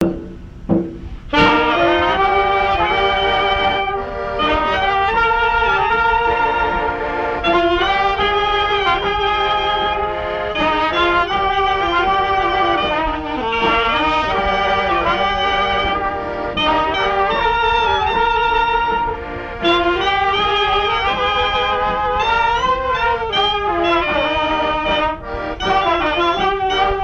gestuel : à marcher
circonstance : fiançaille, noce
Pièce musicale inédite